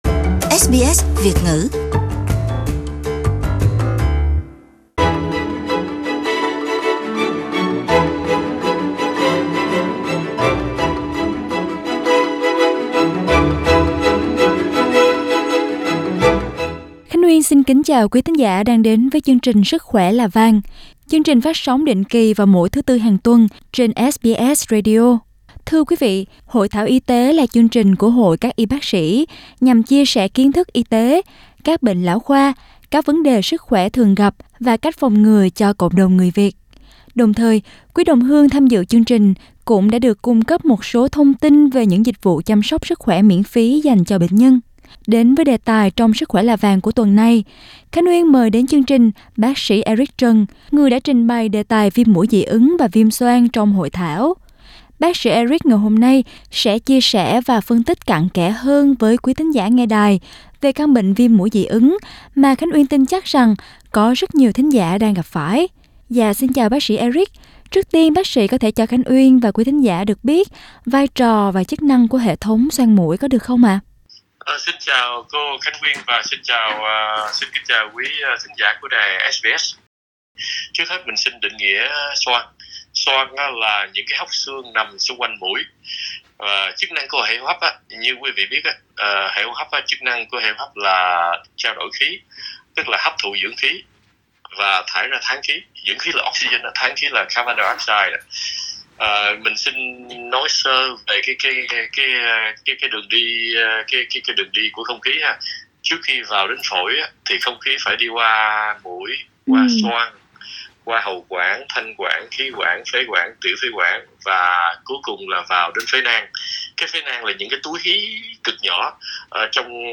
Tạp chí Sức khỏe là Vàng sẽ lần lượt hàng tuần chia sẻ các đề tài đã được thảo luận trong hội thảo để quý đồng hương không có dịp tham dự sẽ vẫn được tìm hiểu các kiến thức y tế và cách phòng tránh bệnh phổ biến.